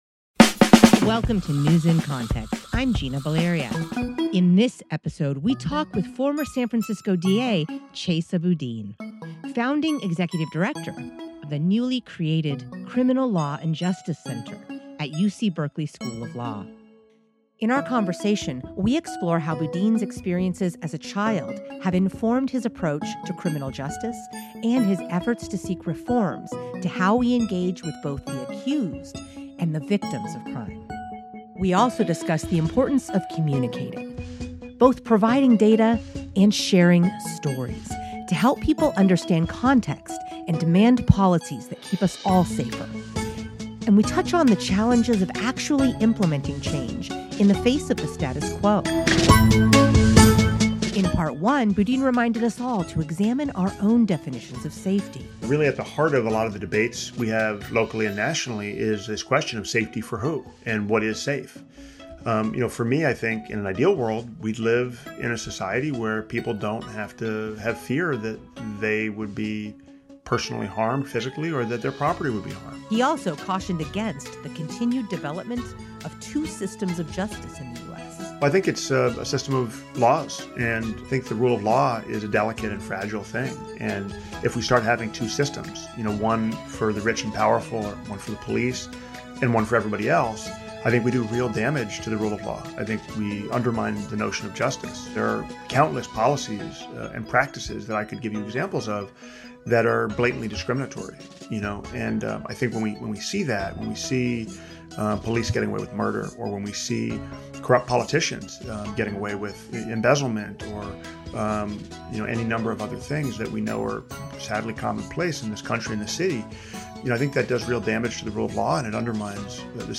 In Part Two of my conversation with Chesa Boudin, we explore how his experiences as a child have informed his approach to criminal justice, and his efforts to seek reforms to how we engage with both the accused and the victims of crime.